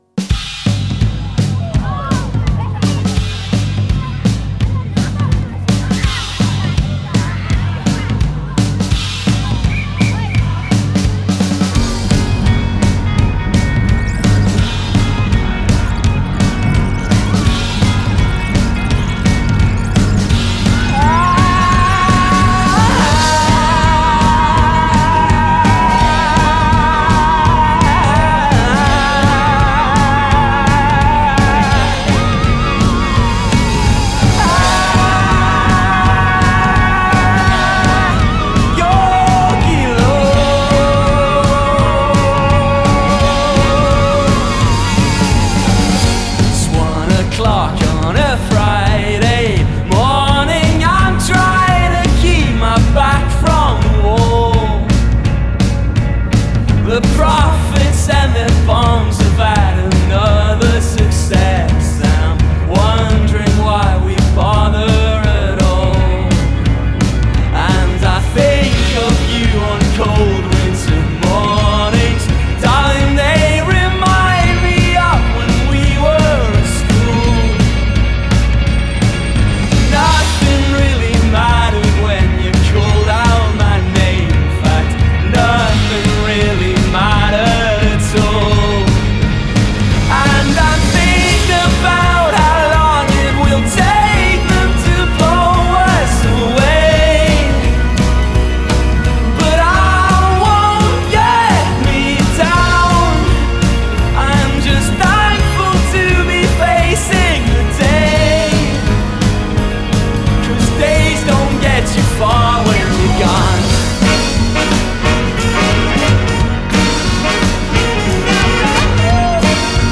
fiati
theremin